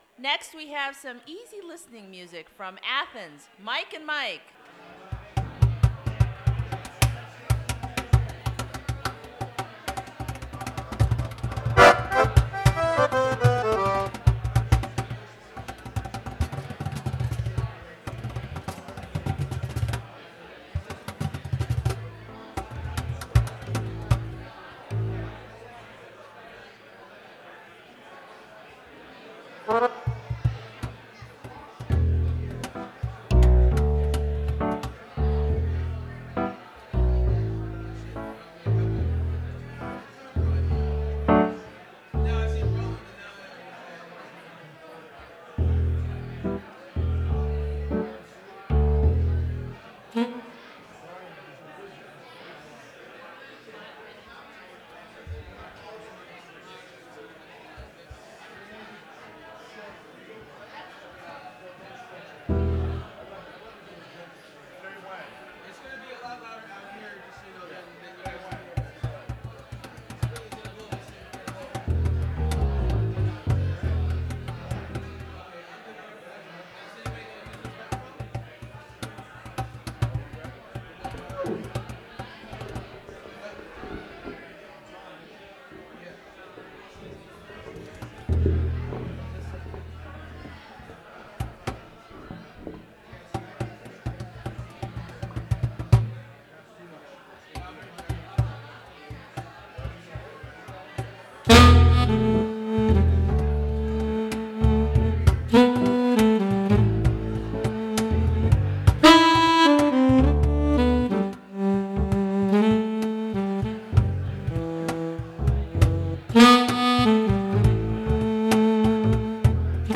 Recorded from WGXC 90.7-FM webstream.